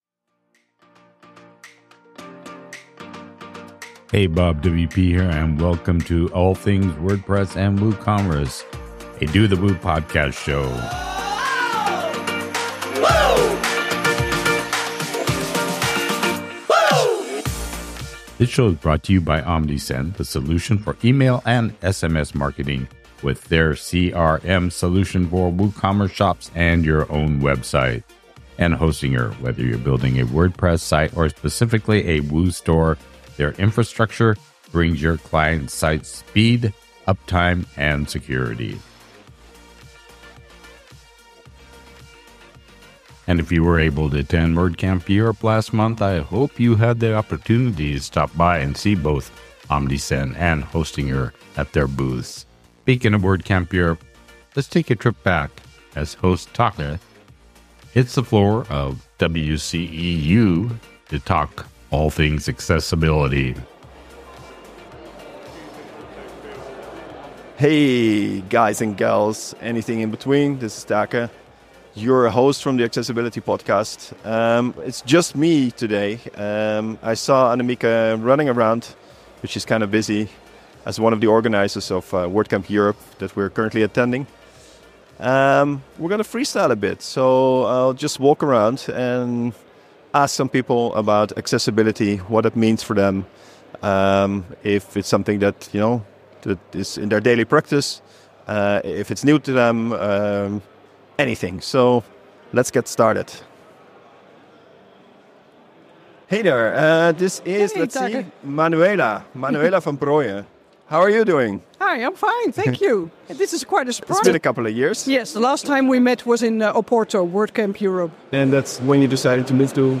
Walking the Floor at WordCamp Europe 2024 Talking Accessibility